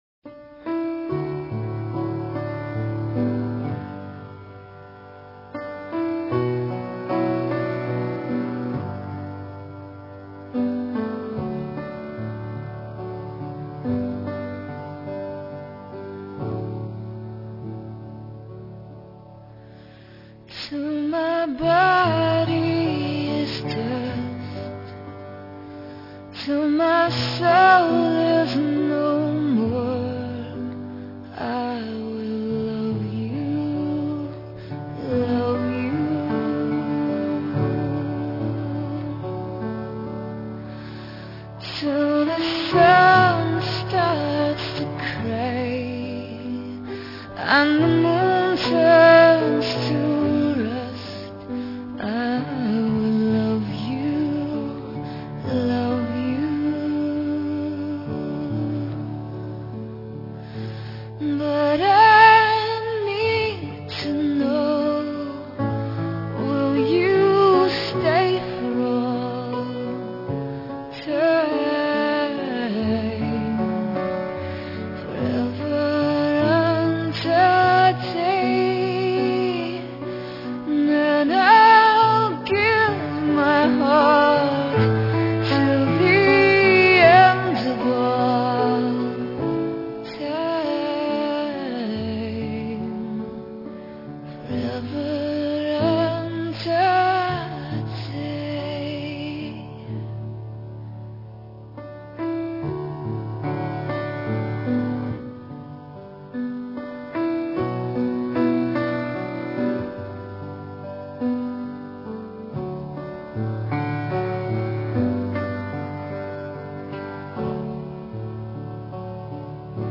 sweet voice